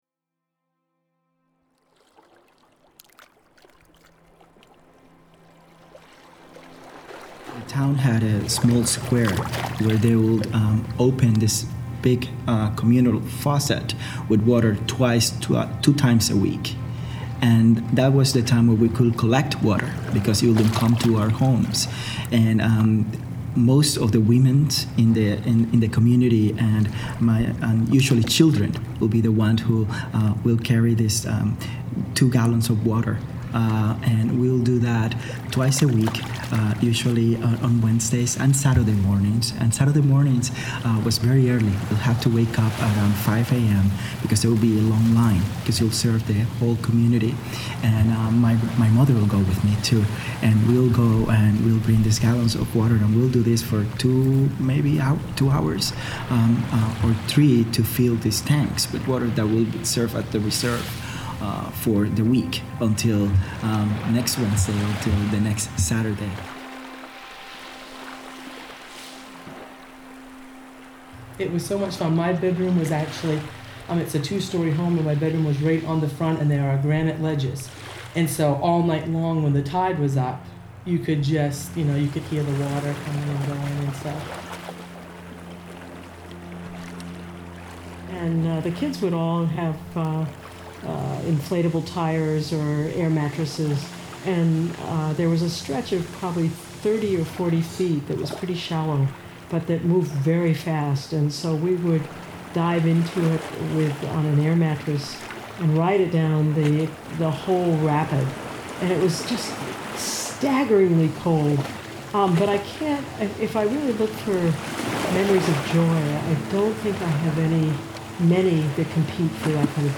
The voices are edited and combined with water sounds and musical elements and play in a continuous loop throughout the gallery.